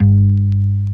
A#_07_Bass_06_SP.wav